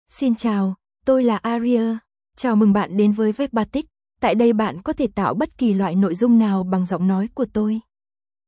AriaFemale Vietnamese AI voice
Aria is a female AI voice for Vietnamese (Vietnam).
Voice sample
Listen to Aria's female Vietnamese voice.
Aria delivers clear pronunciation with authentic Vietnam Vietnamese intonation, making your content sound professionally produced.